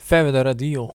pronunciation) is a former municipality of Friesland in the northern Netherlands.